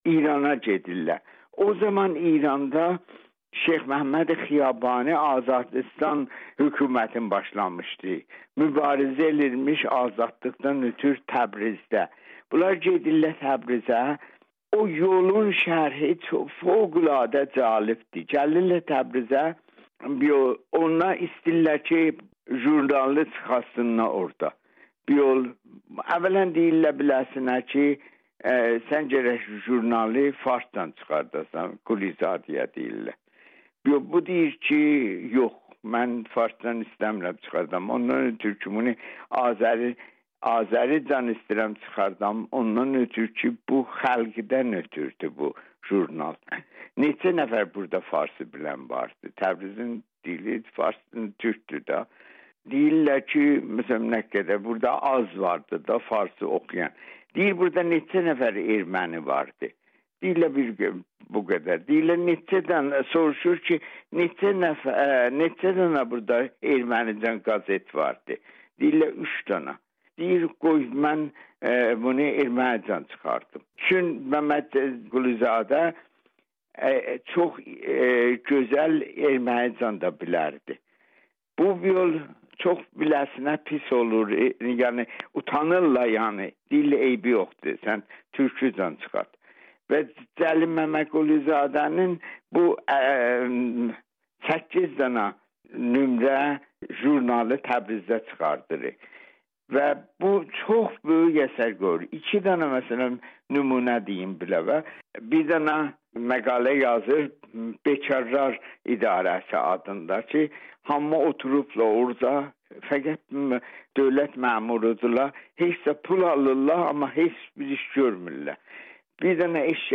telefonla söhbətləşdik.